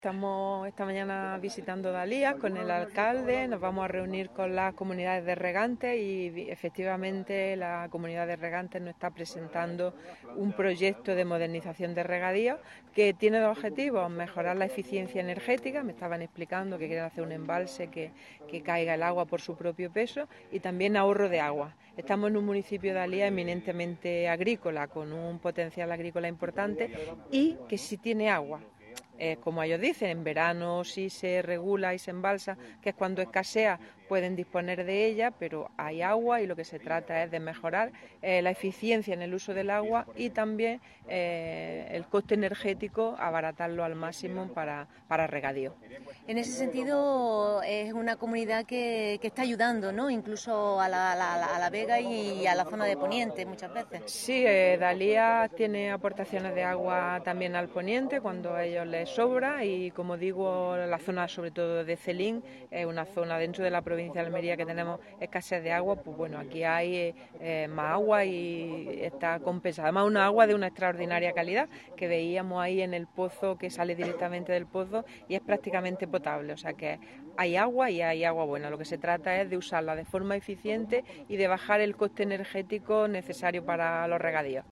Declaraciones de la consejera en su visita a la Comunidad de Regantes Los Llanos de Dalías